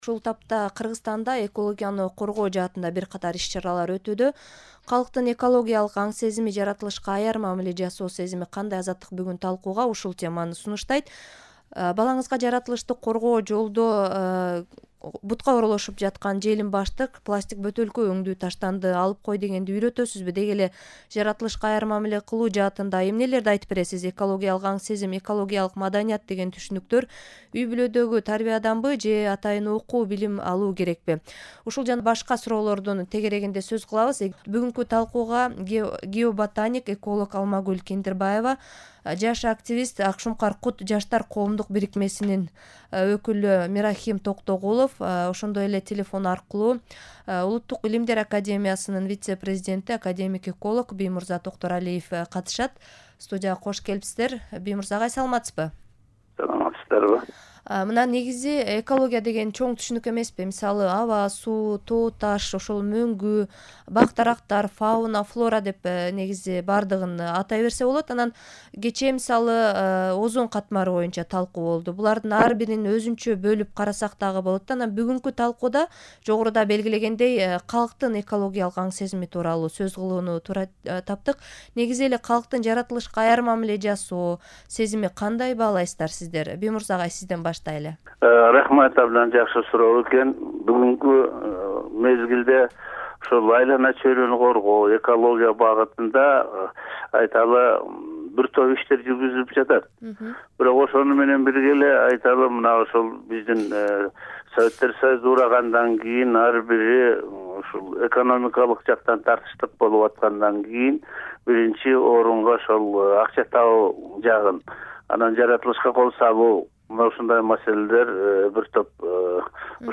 Жаратылыш тууралуу талкуу